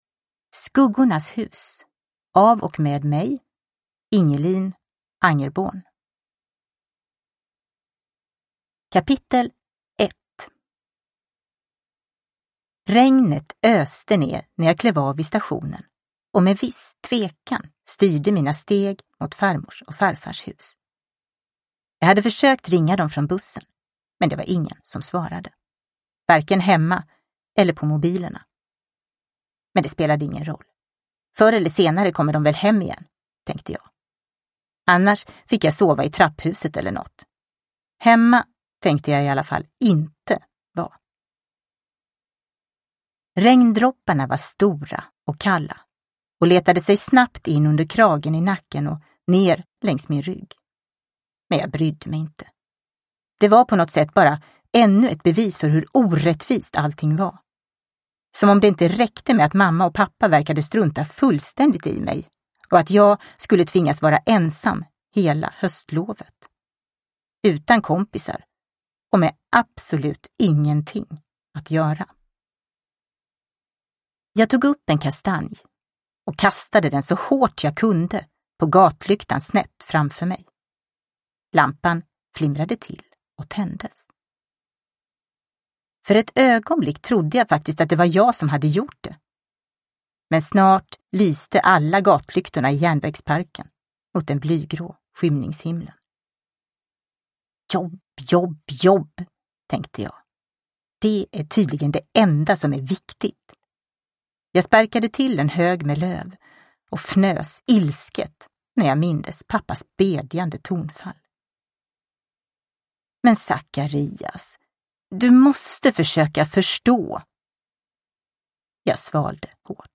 Skuggornas hus – Ljudbok – Laddas ner